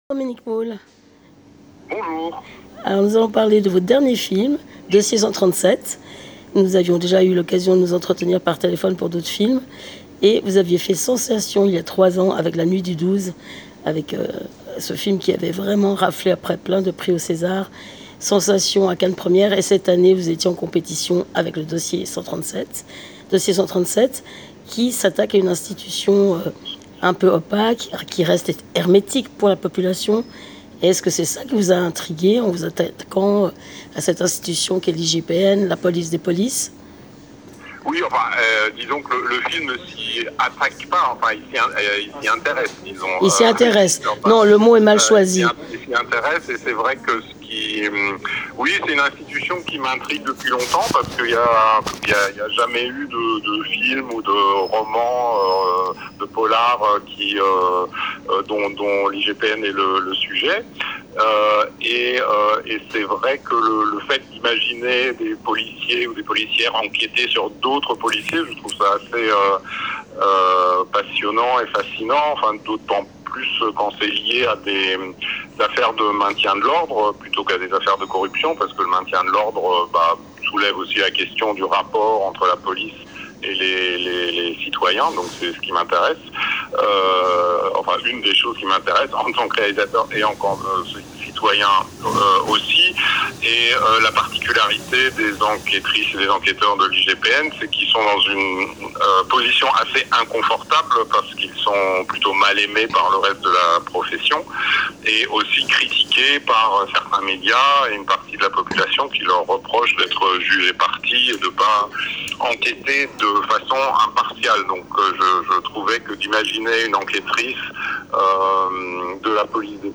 Dossier 137 de Dominik Moll immerge le public au cœur de l'IGPN durant le mouvement des Gilets jaunes. Rencontre - j:mag